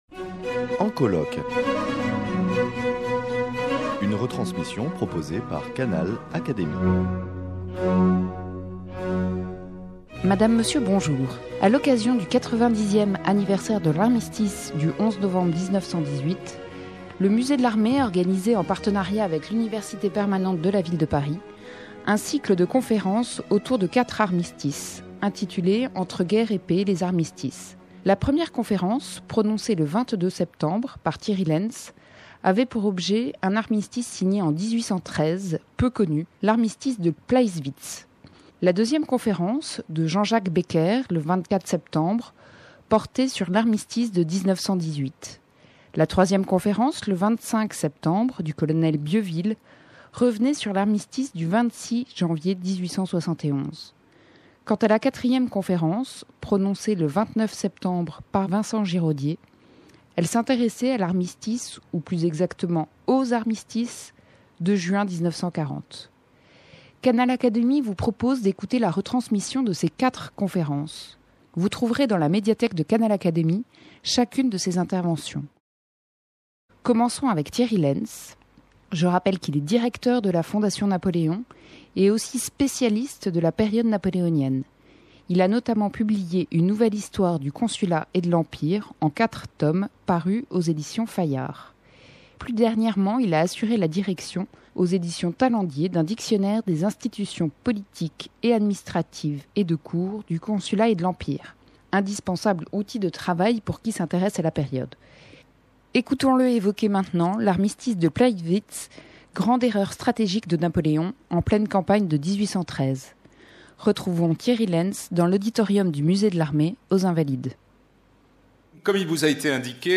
A l’occasion du 90e anniversaire de l’armistice du 11 novembre 1918, le musée de l’Armée a organisé, en partenariat avec l’Université permanente de la ville de Paris, un cycle de conférences autour des suspensions d’armes ou armistices les plus importants de l’histoire moderne ou contemporaine de la France. Thierry Lentz choisit d’aborder un armistice peu connu et pourtant fatal à Napoléon, l’armistice de Pleiswitz signé en 1813.